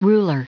Prononciation du mot ruler en anglais (fichier audio)
Prononciation du mot : ruler